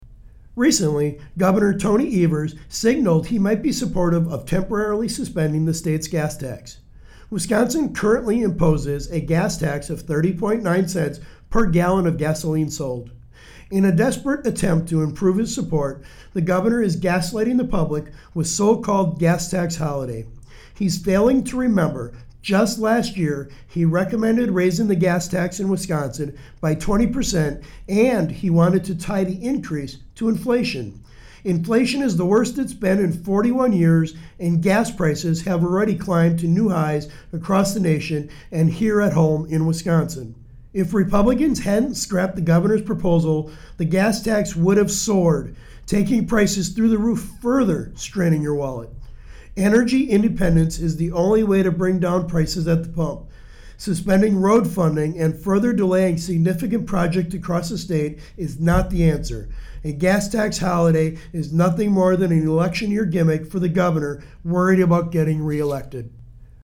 Weekly GOP radio address: Sen. Feyen says the “Gas Tax Holiday” is an election year gimmick - WisPolitics